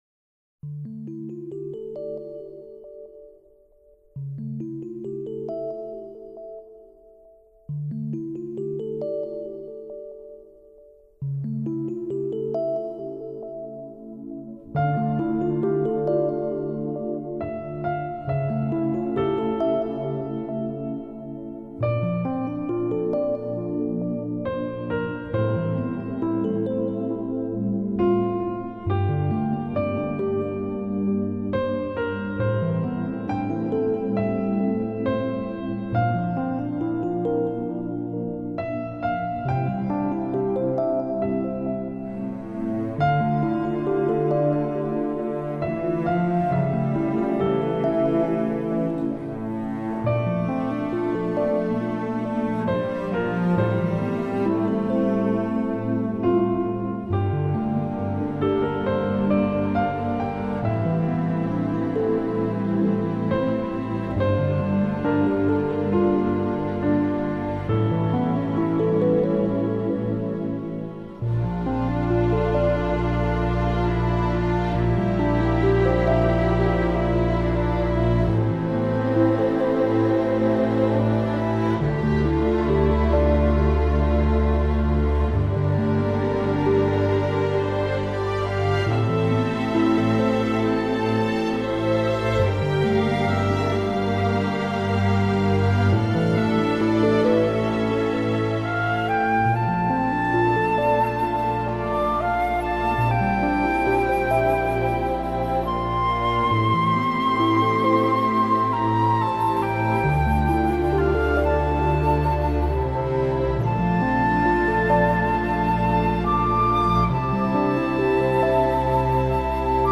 他们的音乐充满图画色彩，清新静怡、精致脱俗，是现代都市人安抚、净化心灵的良药，有着“治疗音乐”之称。